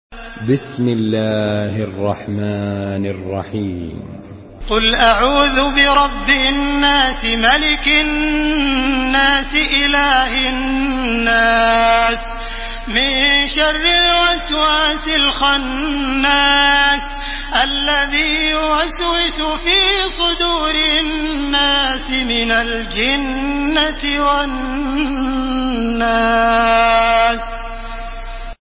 Makkah Taraweeh 1431
Murattal